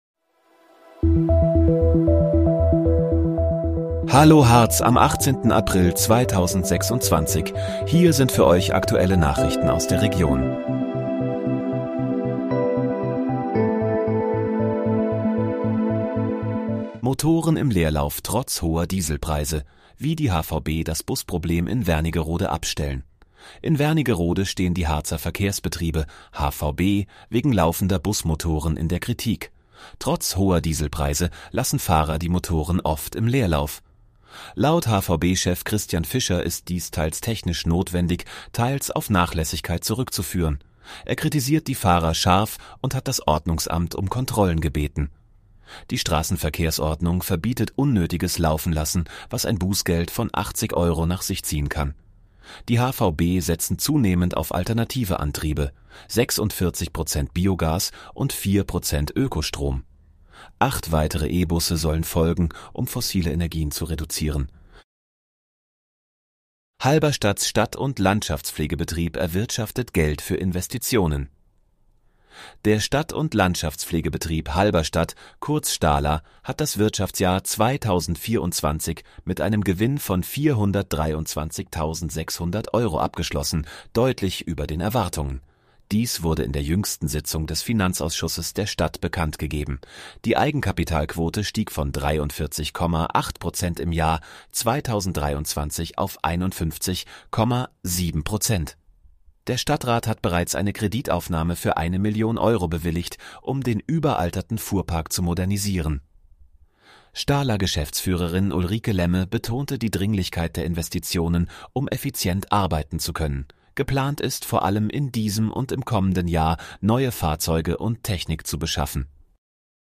Hallo, Harz: Aktuelle Nachrichten vom 18.04.2026, erstellt mit KI-Unterstützung